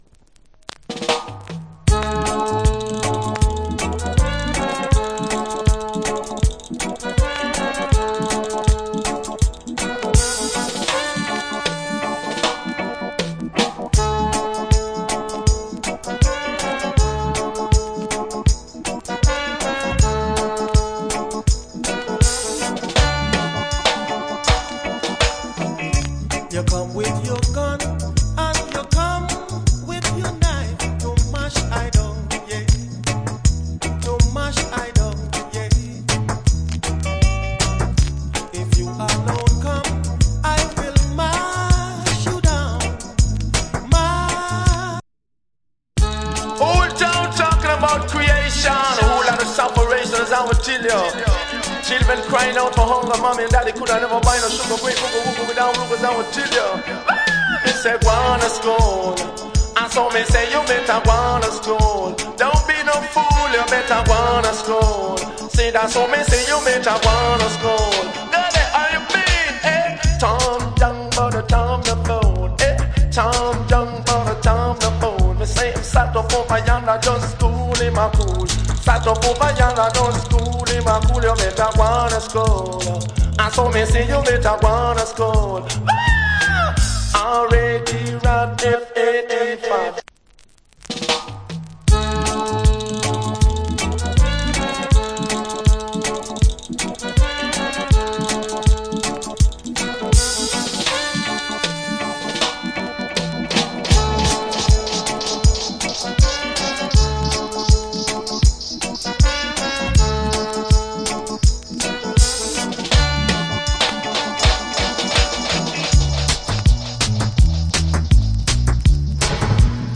Cool Reggae Vocal. With DJ